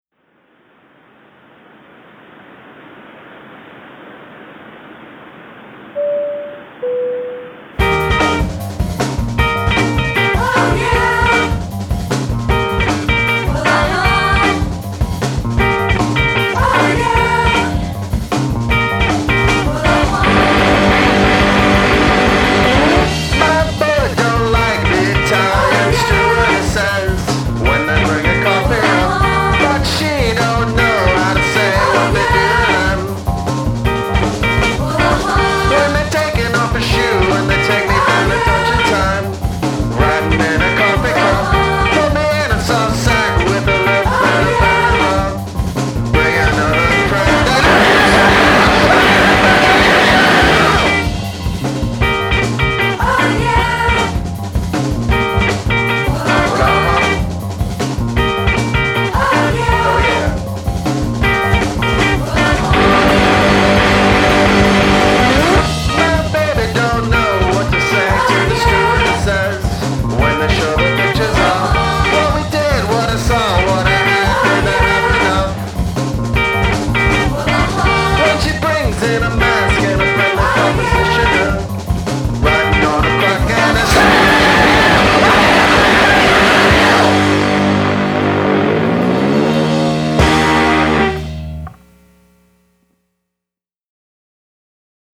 raw energy with lots of nice catchy hooks